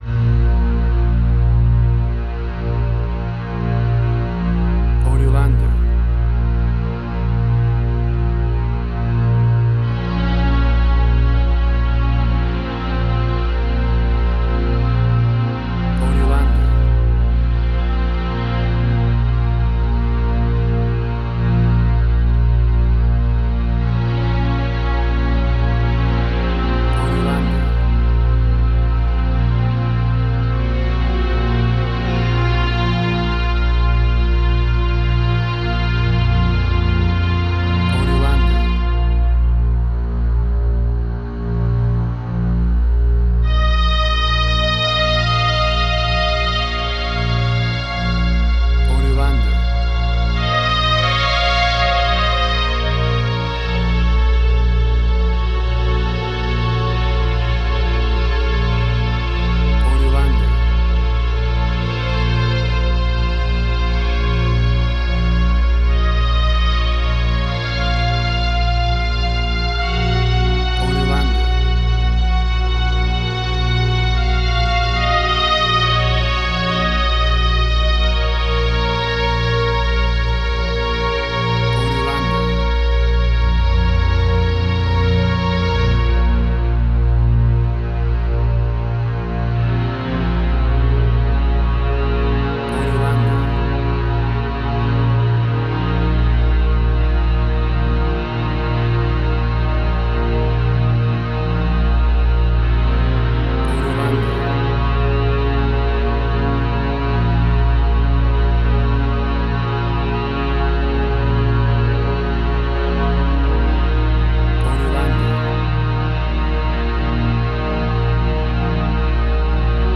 WAV Sample Rate: 32-Bit stereo, 44.1 kHz